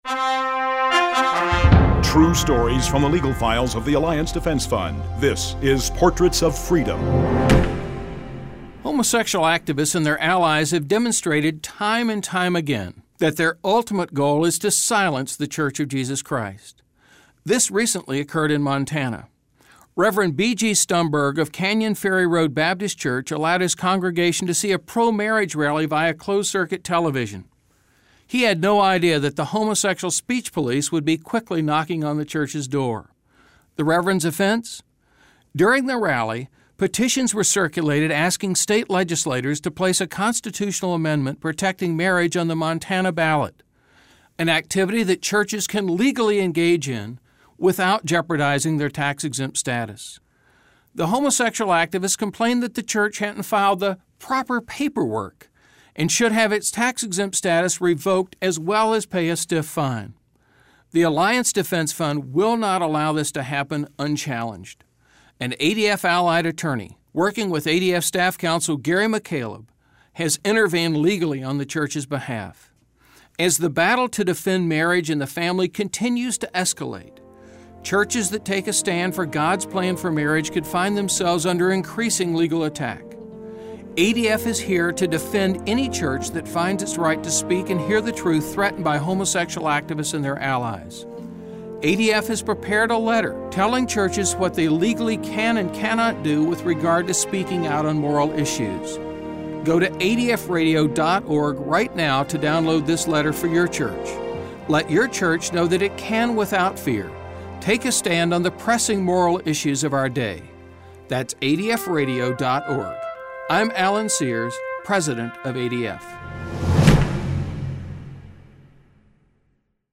ADF Radio Clip: